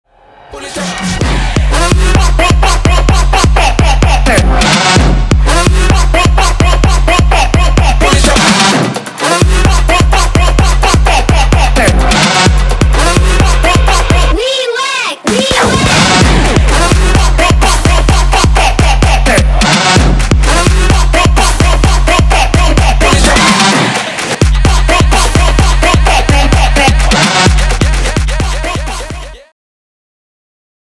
ритмичные
Electronic
EDM
динамичные
Стиль: jungle terror